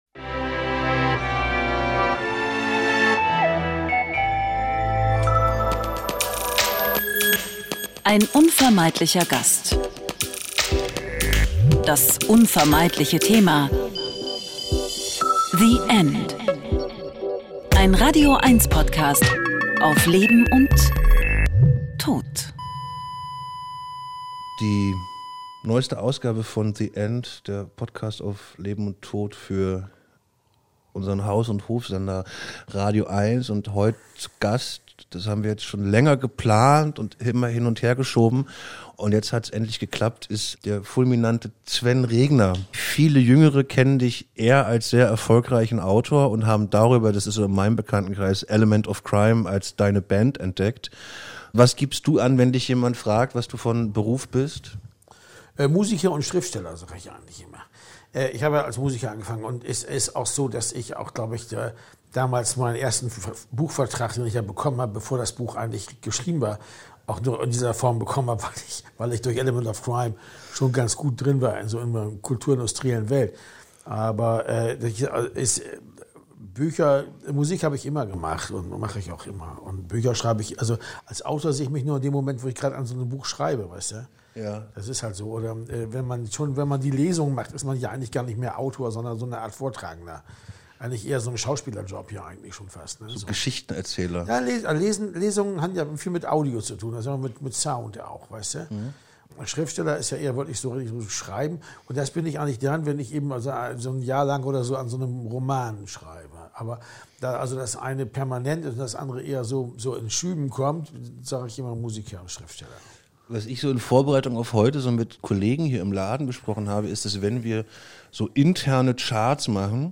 Ein Gespräch darüber, wie wichtig Trauerfeiern und wie unwichtig Grabmale für ihn sind und über die Kraft, die wir daraus schöpfen zu wissen, dass unsere Zeit endlich ist.